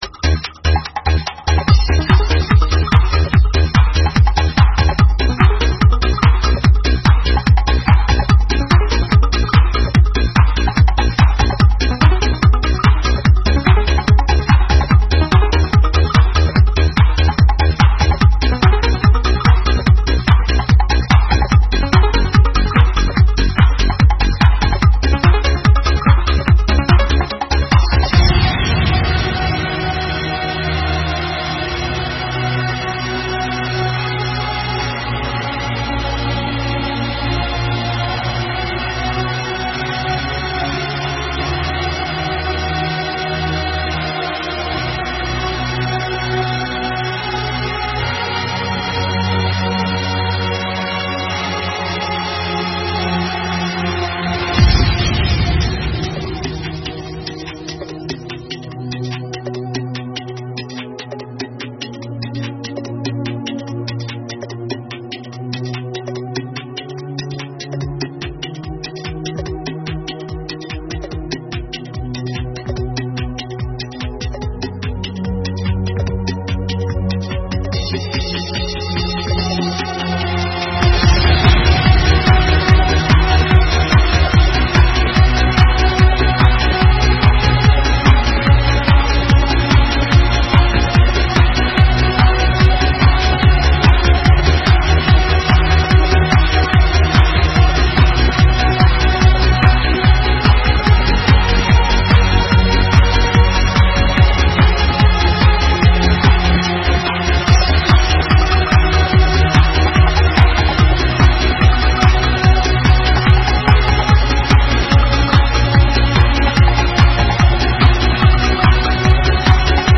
TODO UN PELOTAZO DEL PROGRESSIVE¡¡